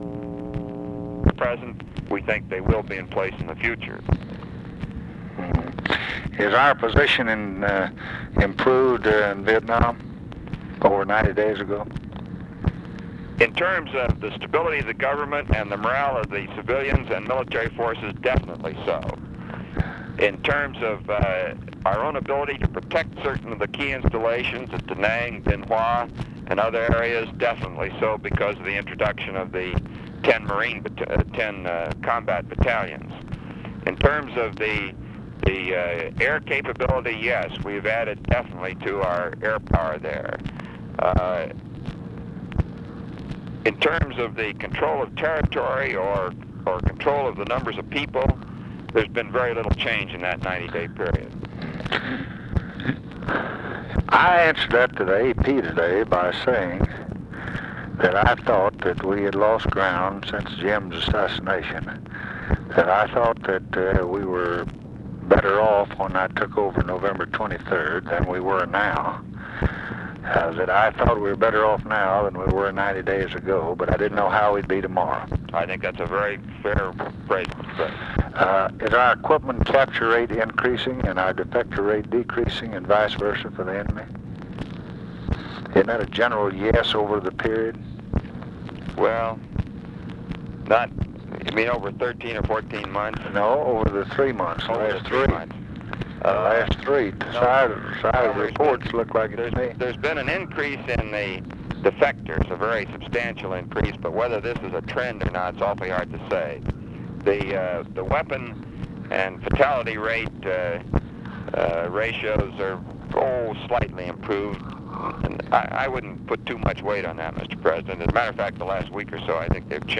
Conversation with ROBERT MCNAMARA, May 12, 1965
Secret White House Tapes